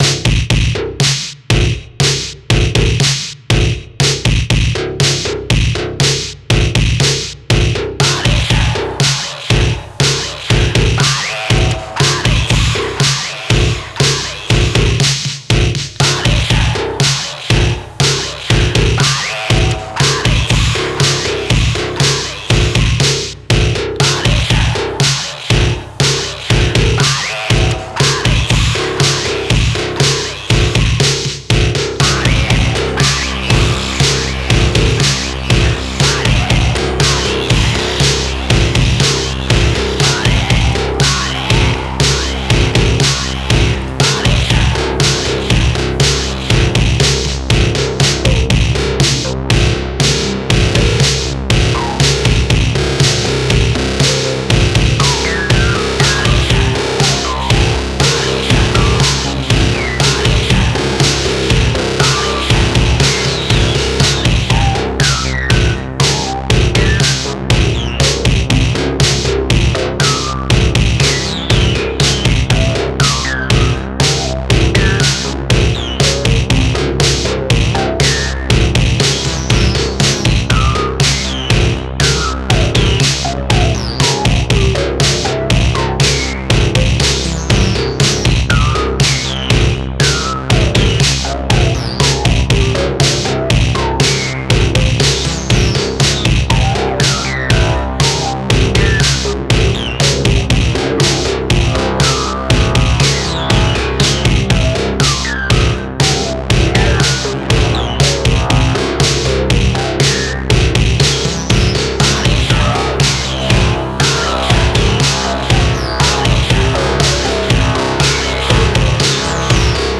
Música tradicional brasileira